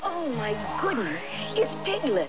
The voices of Pooh and his friends